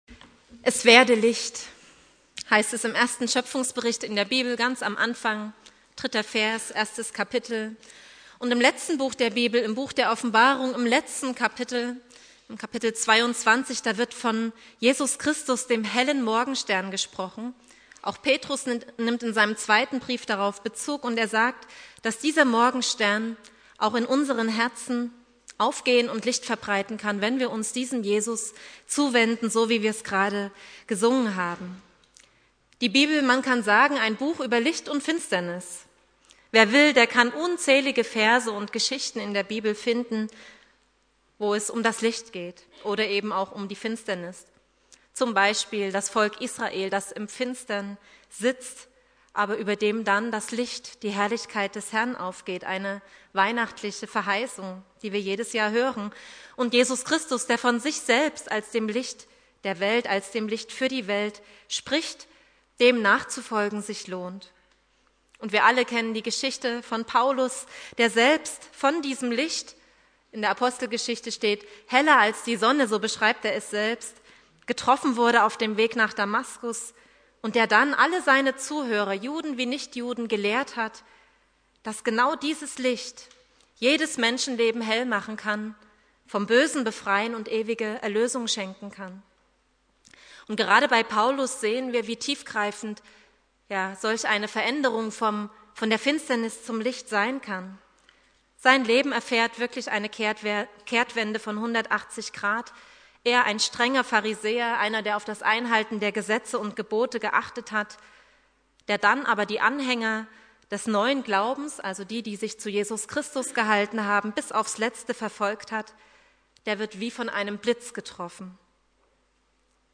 Thema: "Licht verändert alles" Inhalt der Predigt